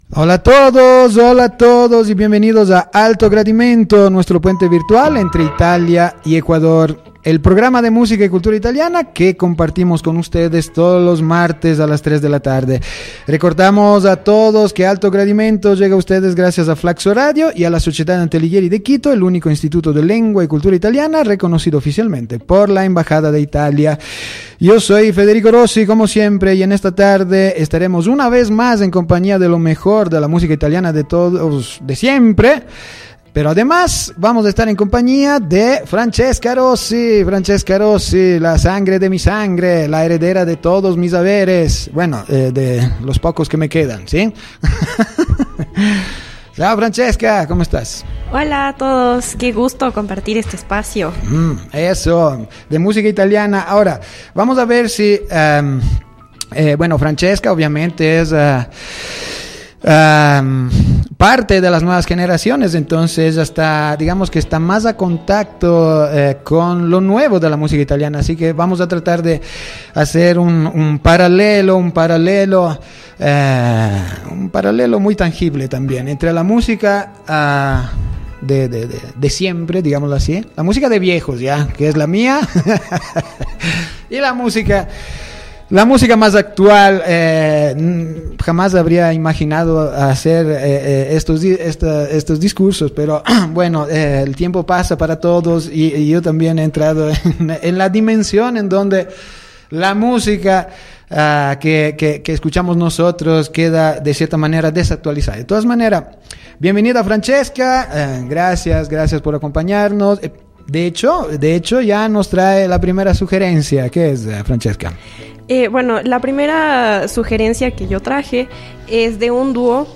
canción de autor italiana